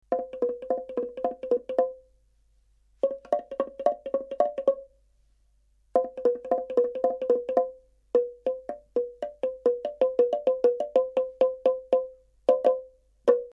竹のスリットドラム
竹のスリットドラム 打楽器 2013年05月19日 やさしく はずむ音がします 長さ ３８センチ ♪ ♪ ♪ ♪ ♪ ↓ 音がはいっています PR Responses0 Responses お名前 タイトル メールアドレス URL パスワード 木筒のチャイム ｜ Home ｜ イヤハーイ笛 （２管）